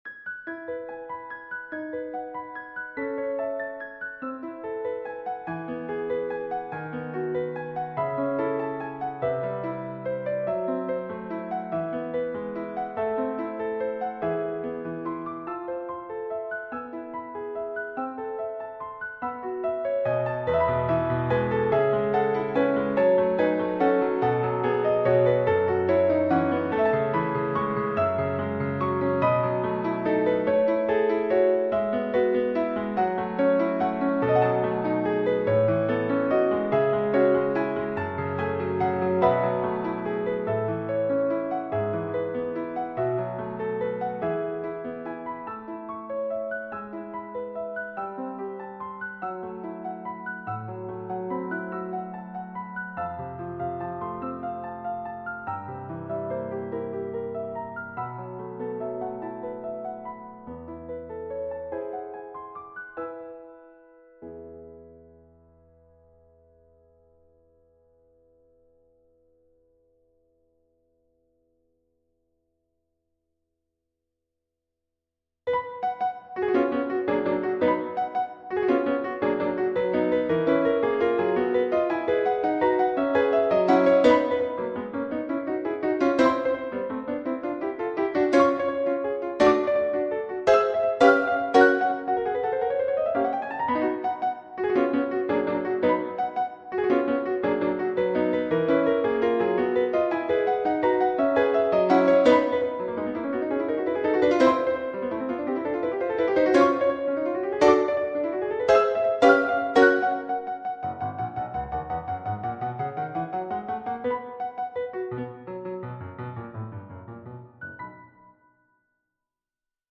Prelude in E Minor 0:00. Scherzo in B Major 1:15. Atmospheric in D Major 2:07. Humoresque in A Major 4:28 . Berceuse in Db Major 5:22 . ...
piano keyboard solo original composition
classical romantic music